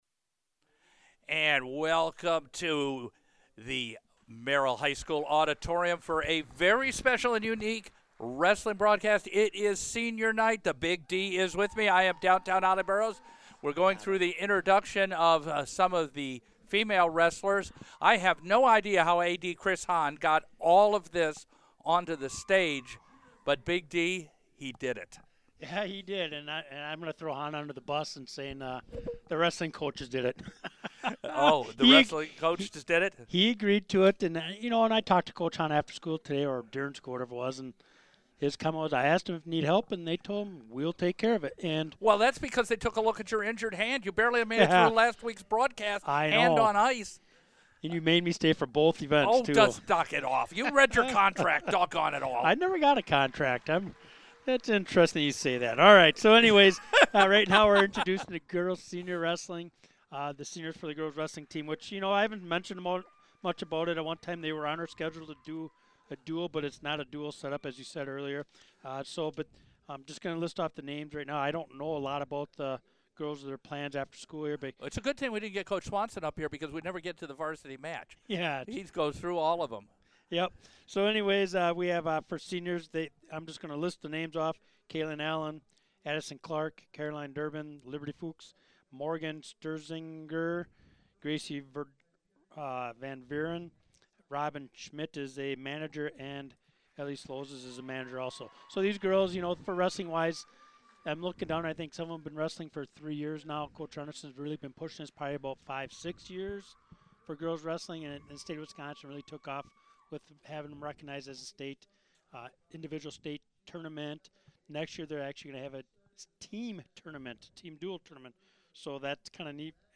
1-27-26 – Lakeland v Merrill Boys Wrestling